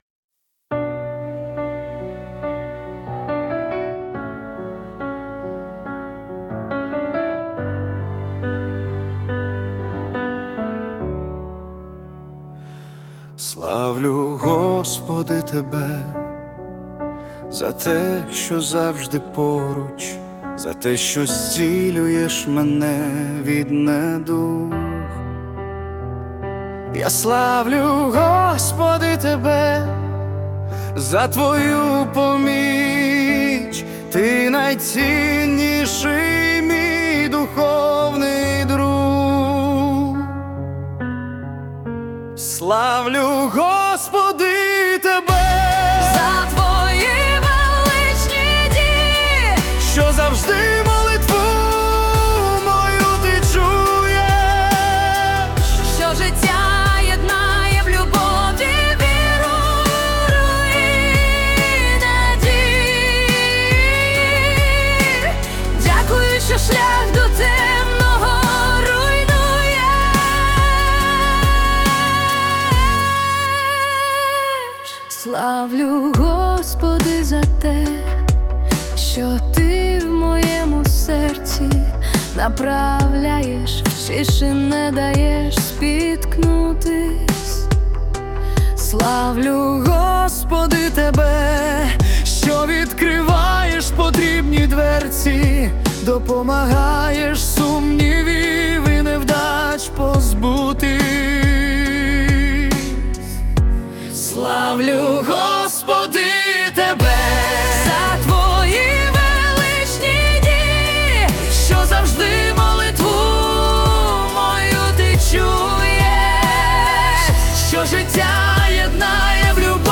ТИП: Пісня
ТЕМАТИКА: Релігійна лірика й духовні вірші